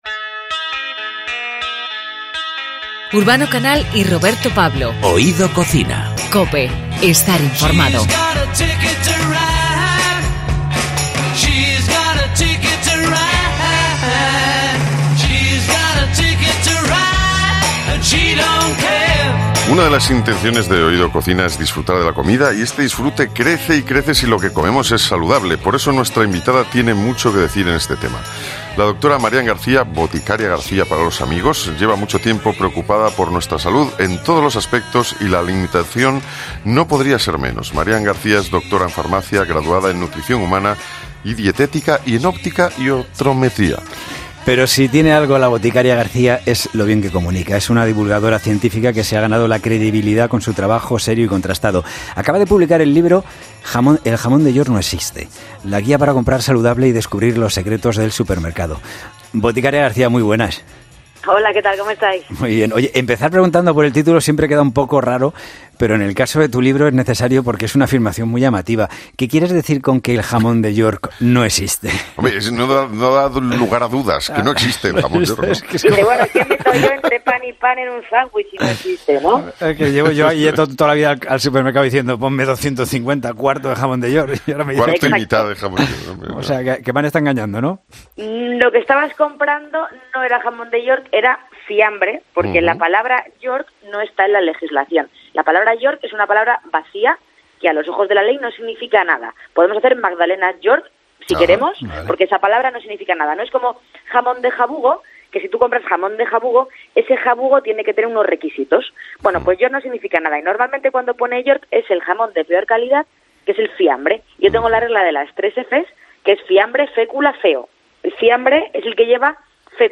Si alguna vez te has planteado si te dan gato por liebre, te conviene escuchar esta entrevista.